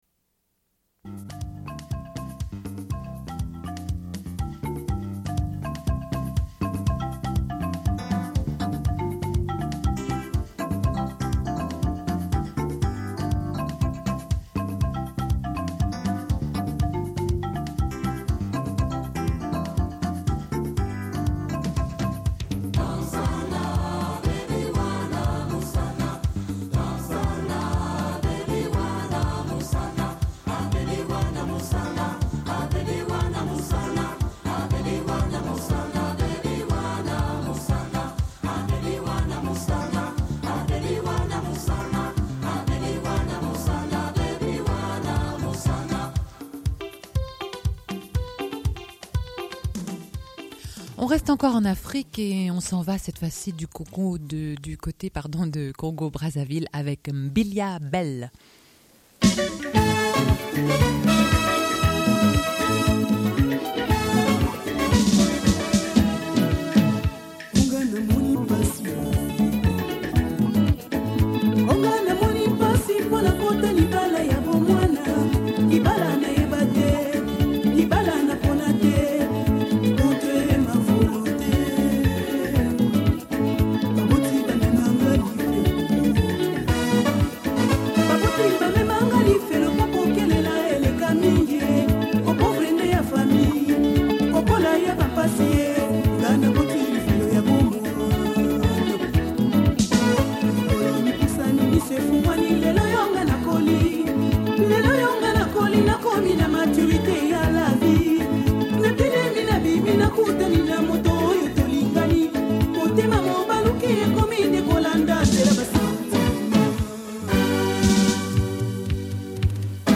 Diffusion de musique. Annonce de l'émission de la semaine suivante, au sujet de l'exposition « Les cahiers au feu » au sujet des souvenirs d'école, au Musée d'ethnographie à Conches.